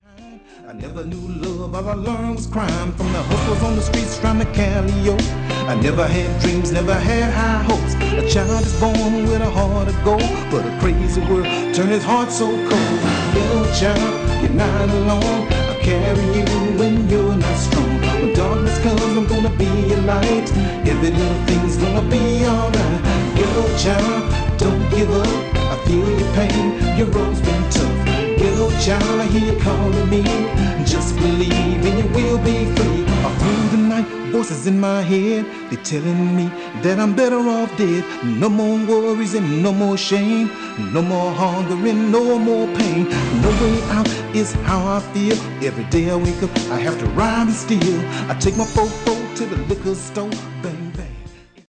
The Nakamichi BX-2 is a very good sounding 2-head cassette deck that comes with Dolby B & C. Nakamichi’s heads were far ahead of what the competition was using back in the days.
Below is a test recordig made with the BX-2 and played back by it:
Nakamichi-BX-2-Test-recording.mp3